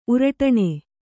♪ uraṭaṇe